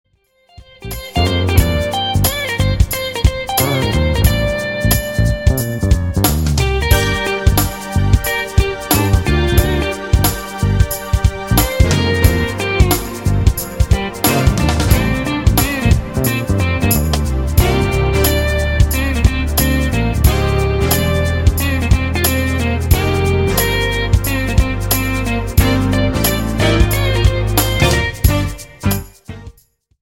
JAZZ  (02.48)